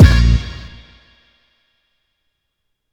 GUnit Hit2.wav